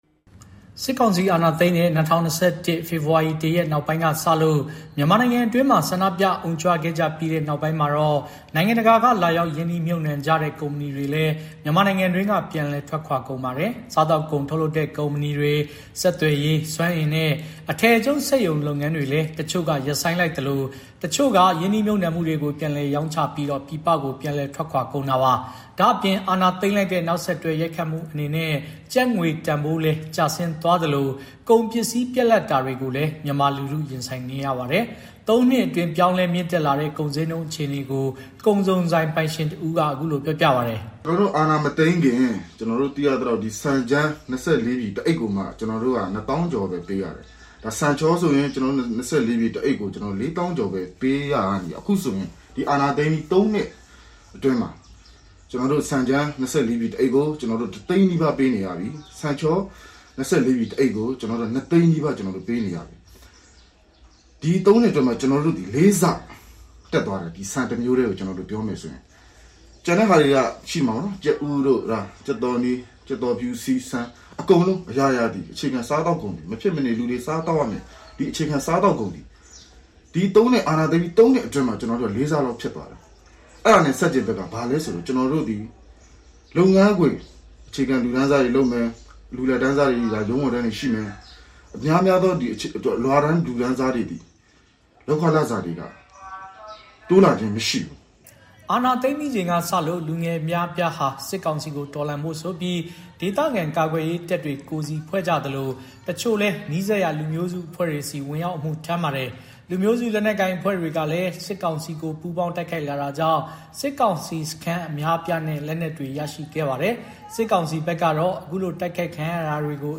စစ်ကောင်စီအာဏာသိမ်းတဲ့ ၂၀၂၁ ဖေဖော်ဝါရီ ၁ ရက်နောက်ပိုင်းကစလို့ မြန်မာနိုင်ငံအတွင်းမှာ ဆန္ဒပြအုံကြွခဲ့ကြပြီး နောက်ပိုင်းမှာတော့ နိုင်ငံတကာက လာရောက် ရင်းနှီးမြှုပ်နှံကြတဲ့ ကုမ္ပဏီတွေလည်း မြန်မာနိုင်ငံတွင်းက ပြန်လည်ထွက်ခွါကုန်ပါတယ်။ စားသောက်ကုန်ထုတ်လုပ်တဲ့ ကုမ္ပဏီတွေ ၊ ဆက်သွယ်ရေး ၊ စွမ်းအင်နဲ့ အထည်ချုပ်စက်ရုံလုပ်ငန်းတွေလည်း တချို့က ရပ်ဆိုင်းလိုက်သလို တချို့က ရင်းနှီးမြှုပ်နှံမှုတွေကို ပြန်လည် ရောင်းချပြီး ပြည်ပကို ပြန်လည်ထွက်ခွါကုန်တာပါ။ ဒါ့အပြင် အာဏာသိမ်းလိုက်တဲ့ နောက်ဆက်တွဲ ရိုက်ခတ်မှုအနေနဲ့ ကျပ်ငွေတန်လည်း ဖိုးကျဆင်းသွားသလို ကုန်ပစ္စည်းပြတ်လပ်တာတွေကိုလည်း မြန်မာလူထု ရင်ဆိုင်နေရပါတယ်။ ၃ နှစ်အတွင်း ပြောင်းလဲမြင့်တက်လာတဲ့ ကုန်ဈေးနှုန်း အခြေအနေကို ကုန်စုံဆိုင်ပိုင်ရှင်တဦးက အခုလို ပြောပြပါတယ်။
နိုင်ငံတွင်း အခြေအနေ မတည်ငြိမ်တာကြောင့် ပြည်ပ ရင်းနှီးမြှုပ်နှံမှုတွေထွက်ခွာတာမှာ မြန်မာနိုင်ငံဘက်က အမြဲတစေ အကာအကွယ်ပေးလေ့ရှိတဲ့ တရုတ်နိုင်ငံက ရင်းနှီးမြှပ်နှံသူတွေေတောင်ပါဝင်ပါတယ်။ ခုအခါ တရုတ်စက်ရုံတချို့ဟာ မြန်မာနိုင်ငံကနေထွက်ခွာပြီး တရုတ်နိုင်ငံမှာ ပြန်လည် ရင်းနှီးမြှပ်နှံကြပါတယ်။ ကမ္ဘာလှည့်ခရီးသွား ဝင်ရောက်မှုနည်းပါးတာတွေကြောင့်လည်း ပြည်တွင်းမှာ အလုပ်အကိုင်ရှားပါးလာပါတယ်။ စားဝတ်နေရေး မပြေလည်တာကြောင့် အစာရေစာပြတ်လပ်ပြီး အသက်ဆုံးရှုံးသွားရတဲ့သူတွေ ရှိလာတယ်လို့ အိမ်ရှင်မတဦးက သူကိုယ်တိုင်ကြုံတွေ့ခဲ့ရတဲ့ အဖြစ်အပျက်ကို အခုလို ပြောပြပါတယ်။